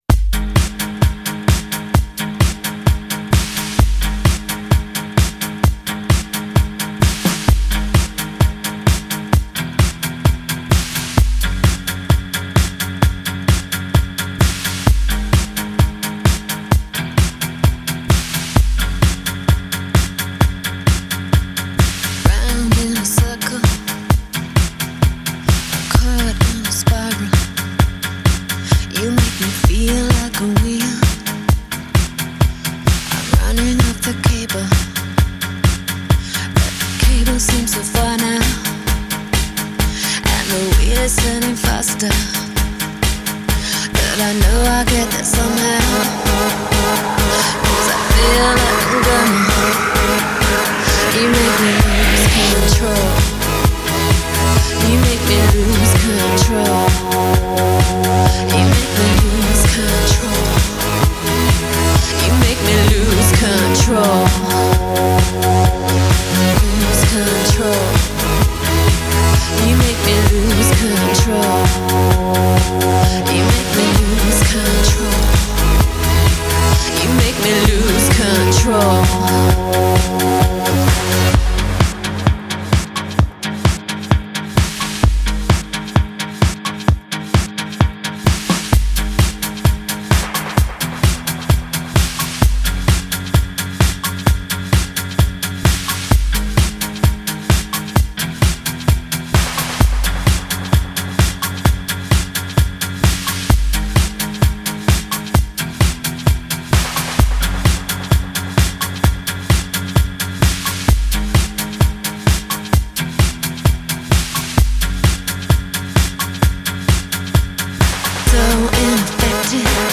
呢喃轻吟的女声、与迷离的电子重拍，挑动着每个为爱疯狂的灵魂。
类型: Electronic